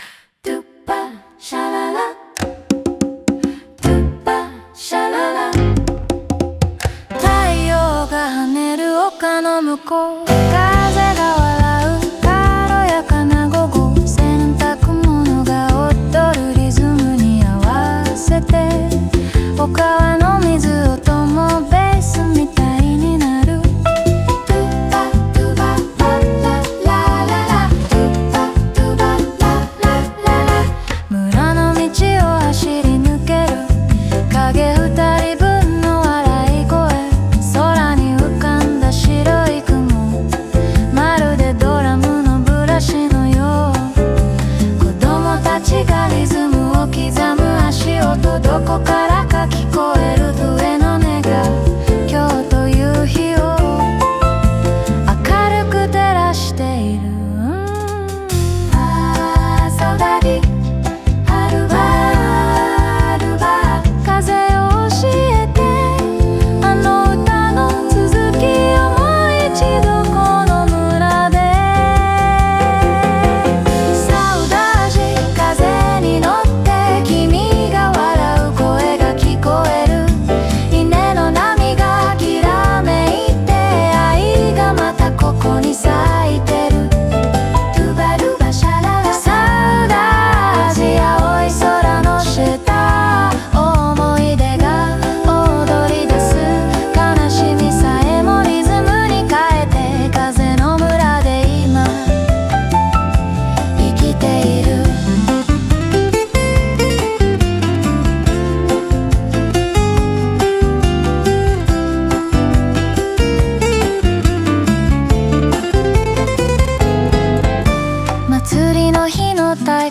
オリジナル曲♪
明るく速いボサノバのテンポに乗せながら、心の奥に残る“サウダージ（郷愁）”が陽気な笑い声とともに蘇る。
テンポは速いが、情感は深く、自然と人の心が共鳴する瞬間を軽快に、しかし叙情的に捉えている。